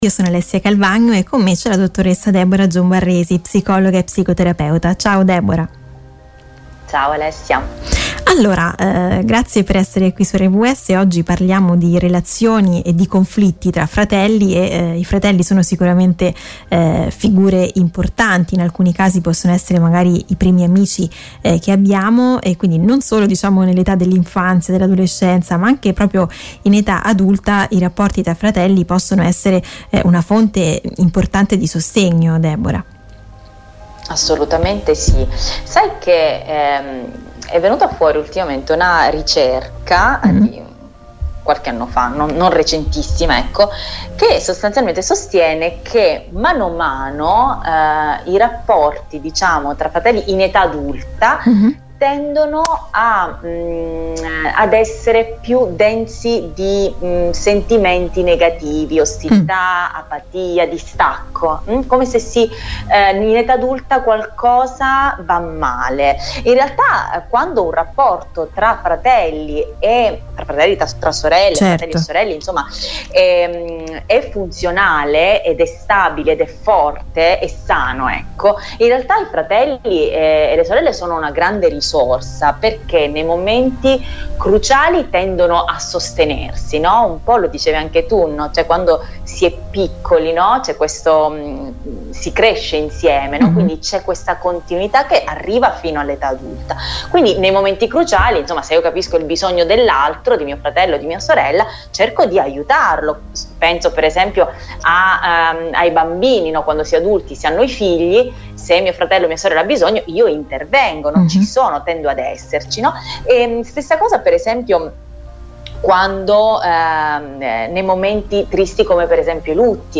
psicologa e psicoterapeuta.